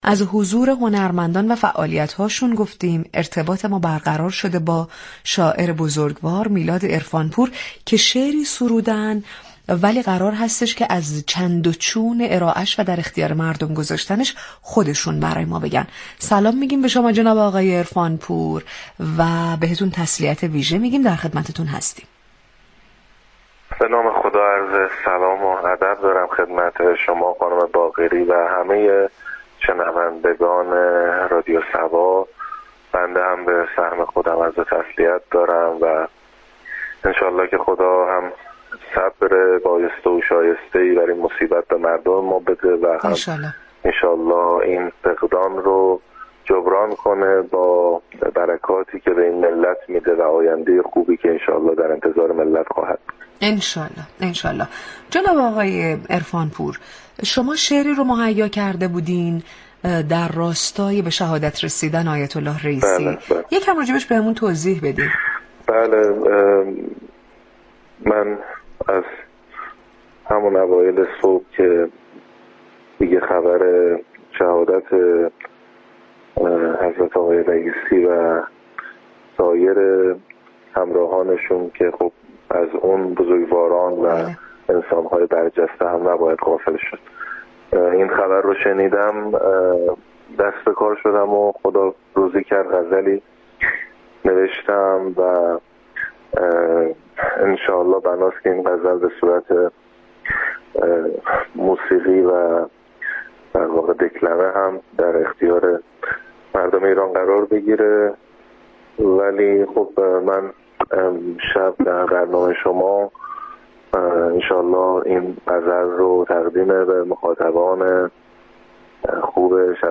این شاعر آیینی بعد از دكلمه شعر با موضوع شهادت آیت الله سید ابراهیم رئیسی بیان كرد، به زودی این شعر در قالب نماهنگ آماده و تقدیم مردم می شود.